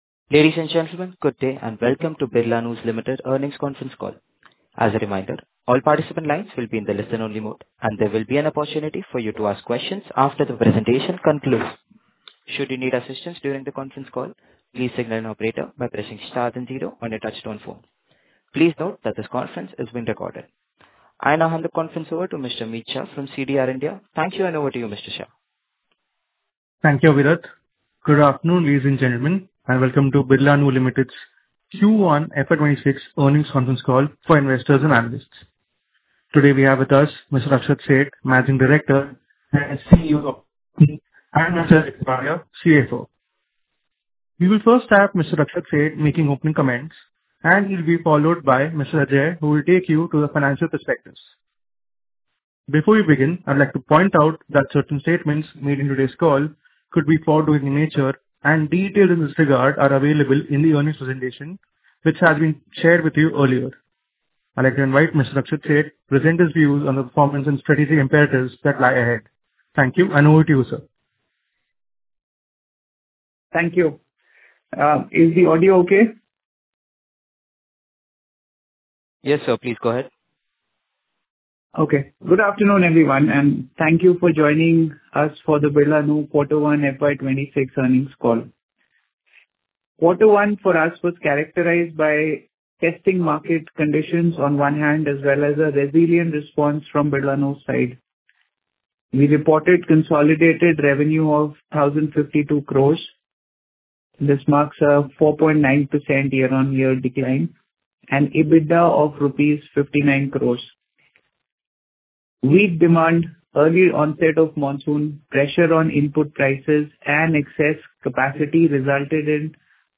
Concalls
birlanu-q1-fy26-concall-audio.mp3